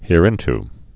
(hîr-ĭnt)